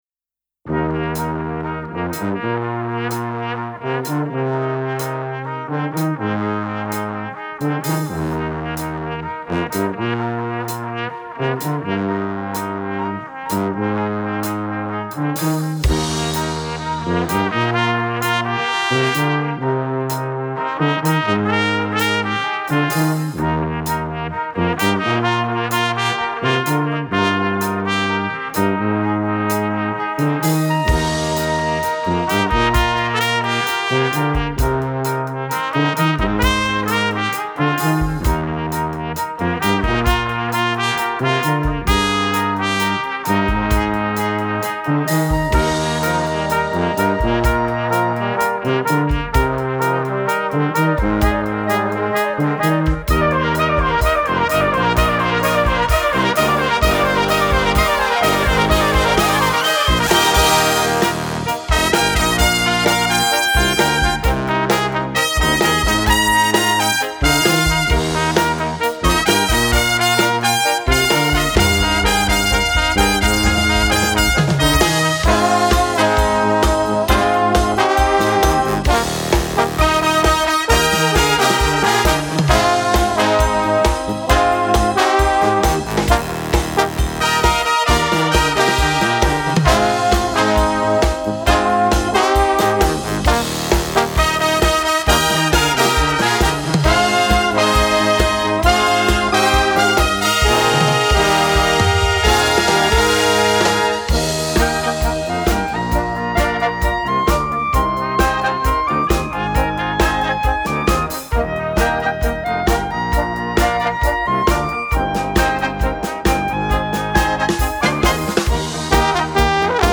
Gattung: Moderner Einzeltitel für kleine Besetzung
Besetzung: Kleine Blasmusik-Besetzung